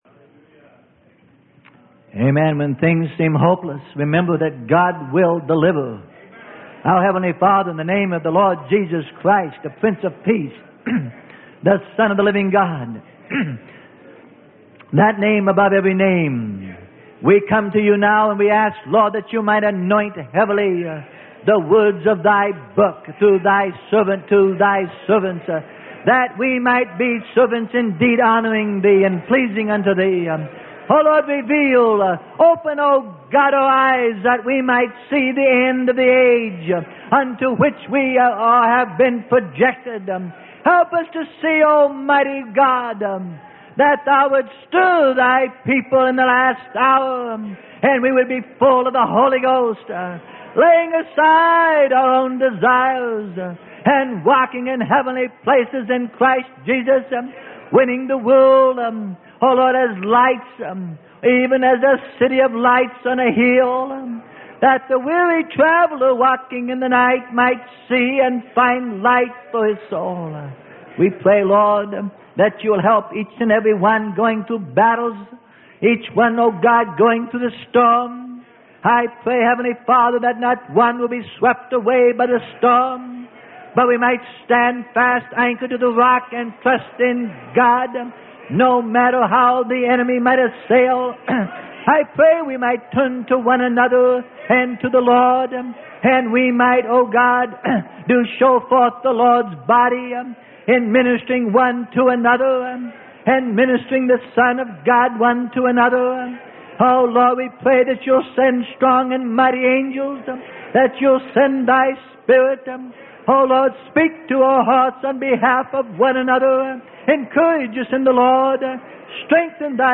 Sermon: Golden Nuggets From the Book of Revealings - Part 4 - Freely Given Online Library